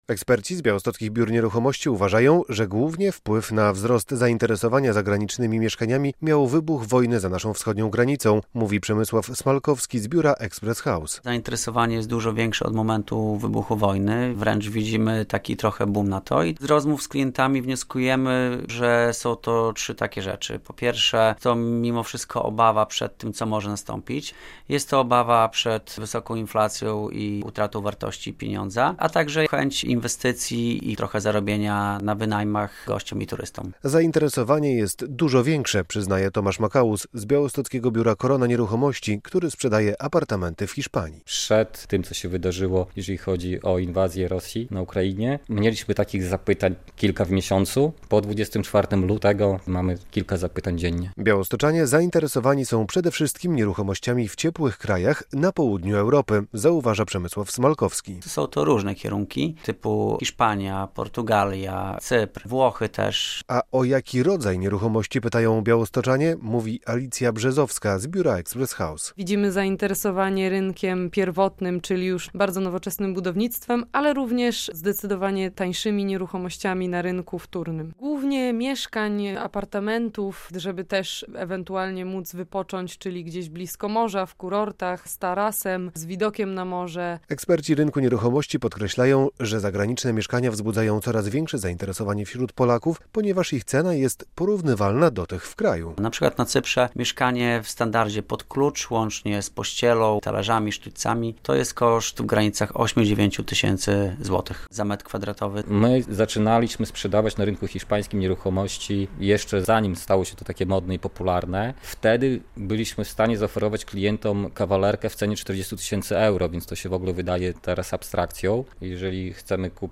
Białostoczanie coraz chętniej inwestują w zagraniczne nieruchomości - relacja